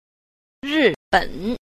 10. 日本 – rìběn – Nhật Bản